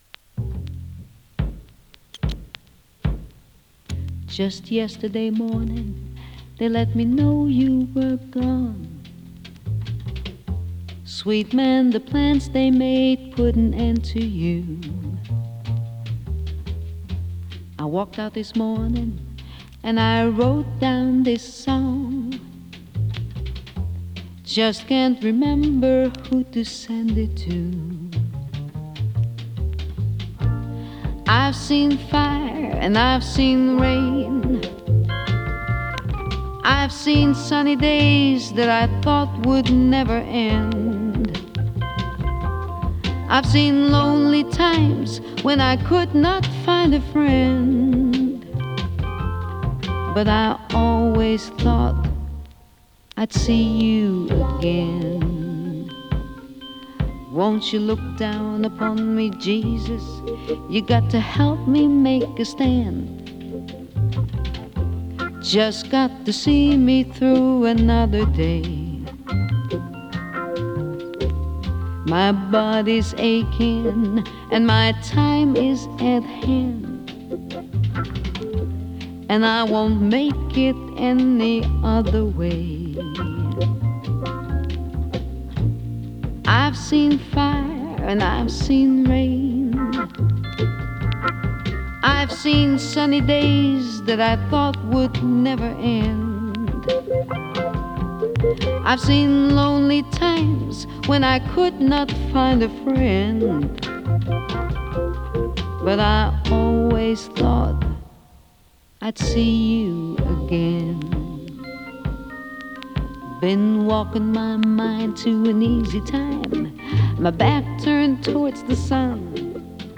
ラウンジ ジャズボーカル
オランダを代表する女性ジャズ・シンガー。